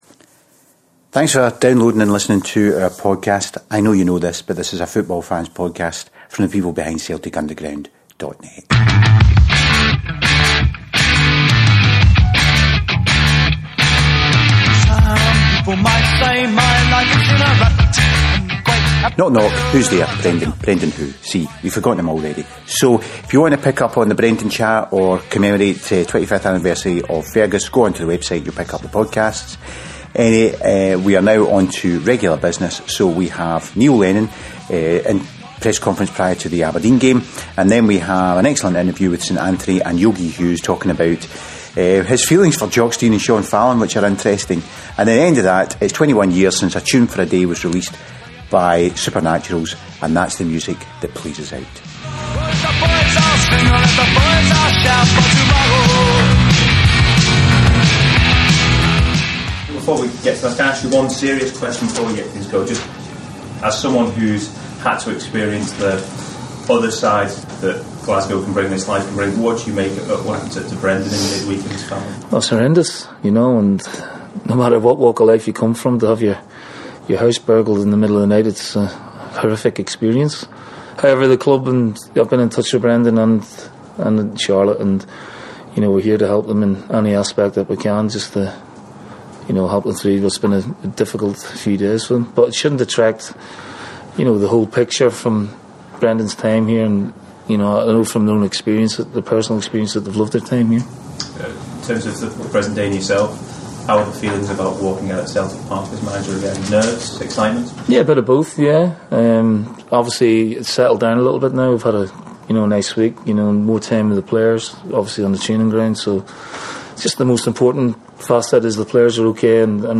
The team is marching towards Gr8ness with Lennon in charge and we have a podcast featuring 2 Celtic legends - Neil Lennon (with his pre-Dons game press conference) and John ‘Yogi’ Hughes. Yogi was at Celtic from 1959 to 1971 before moving on to Crystal Palace and the Sunderland.